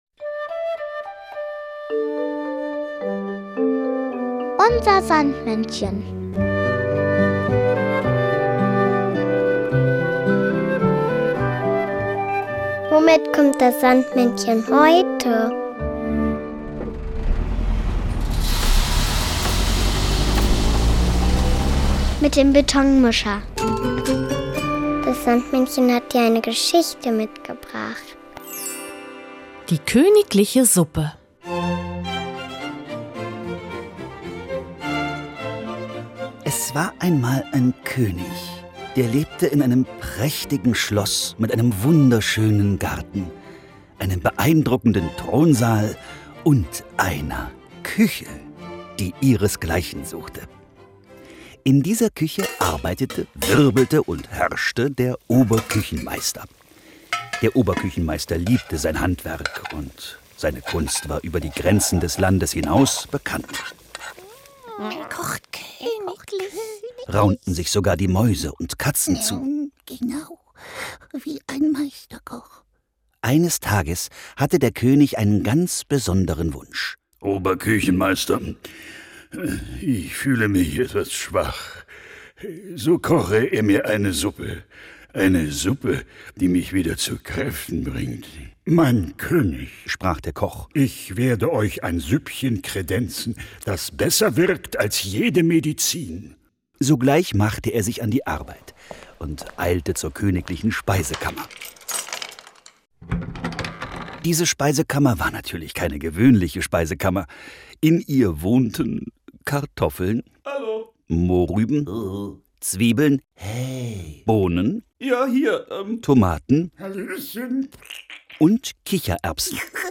Märchen: Die königliche Suppe